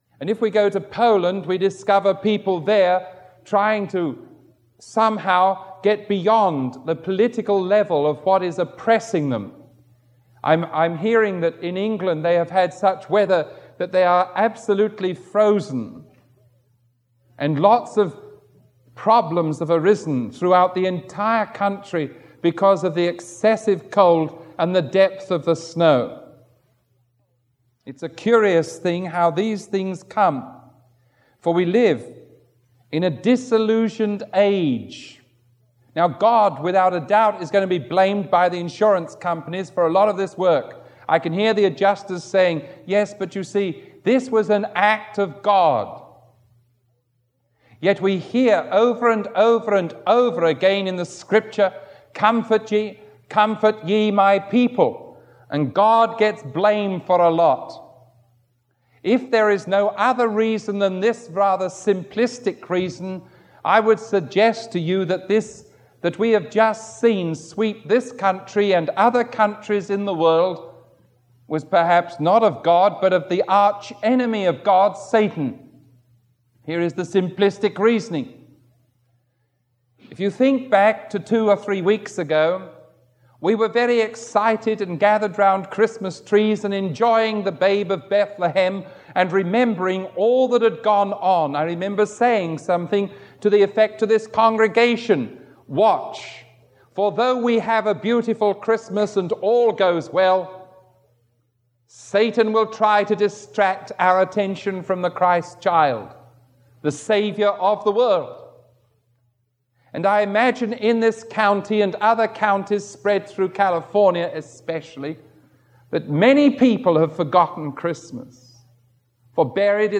Sermon 0476A recorded on January 10